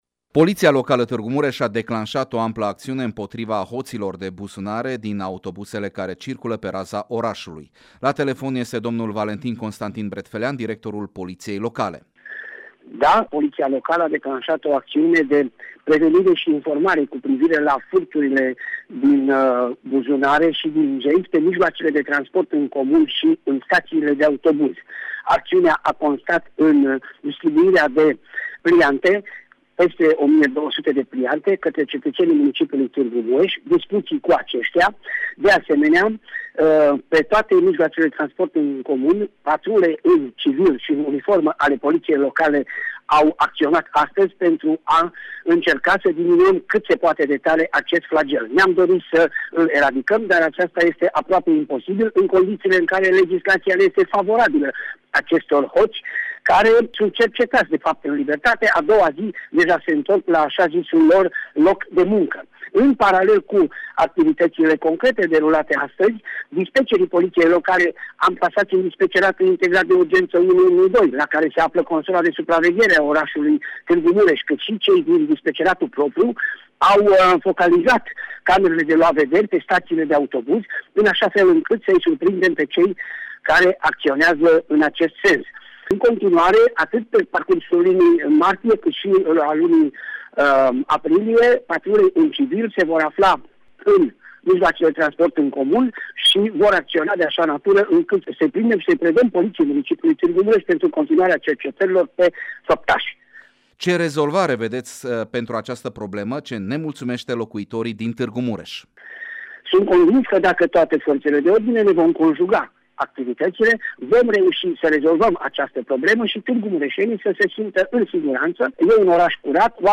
interviu telefonic cu directorul poliției locale Tîrgu Mureș – Valentin Bretfelean: